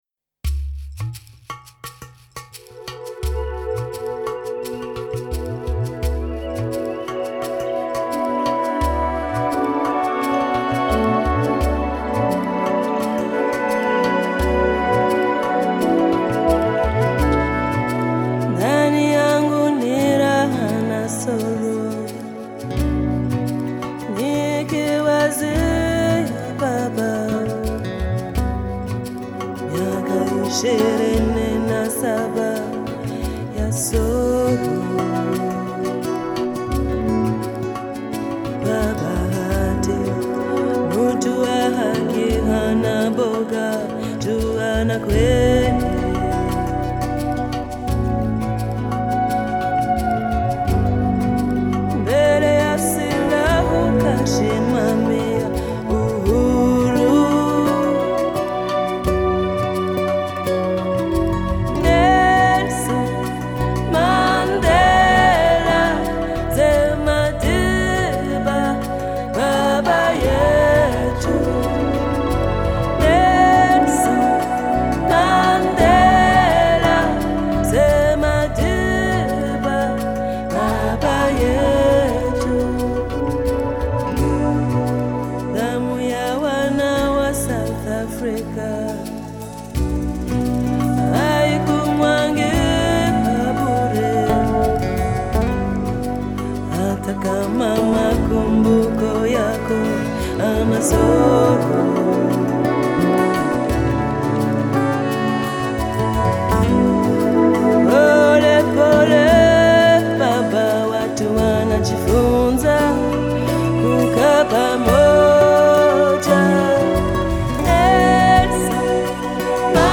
【非洲天籁美声】
类型: Ethnic